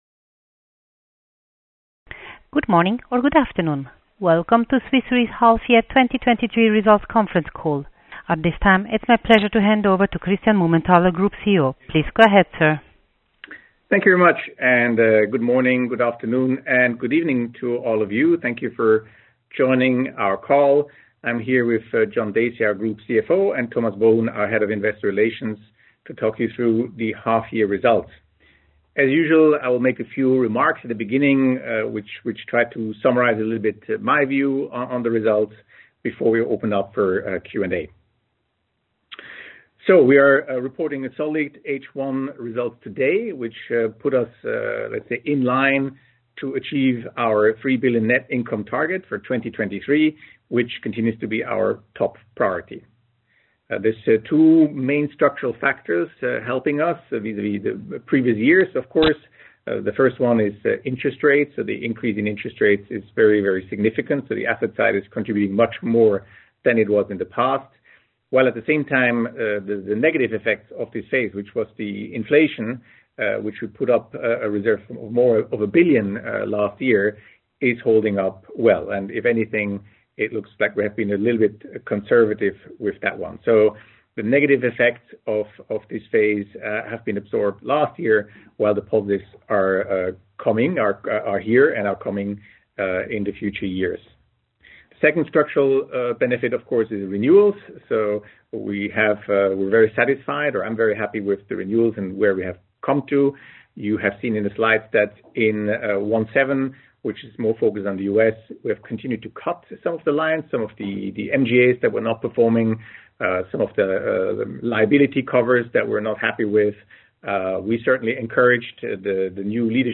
hy-2023-call-recording.mp3